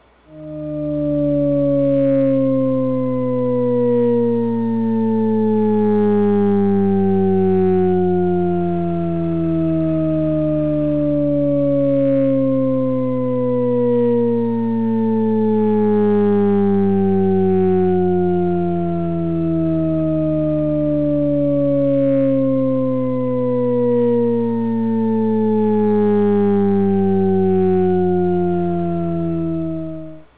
The continuous scale of J-C Risset
J-C Risset created a continuous version.